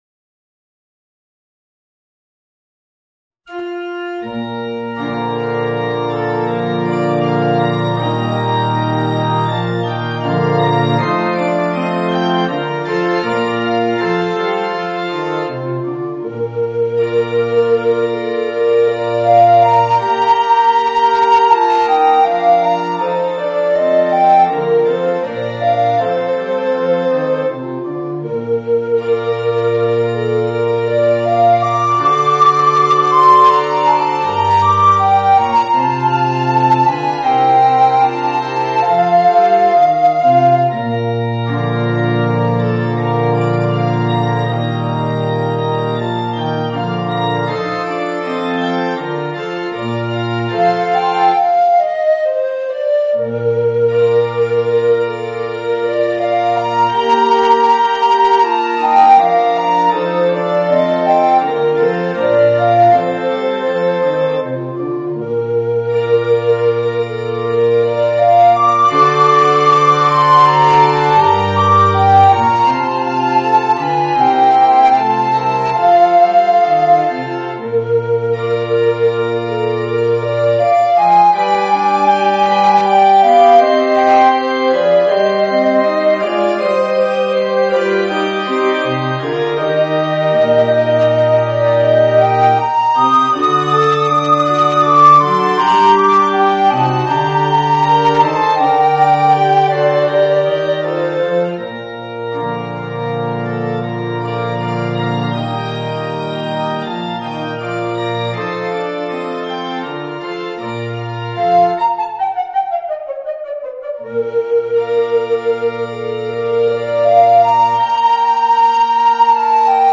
Voicing: Alto Recorder and Piano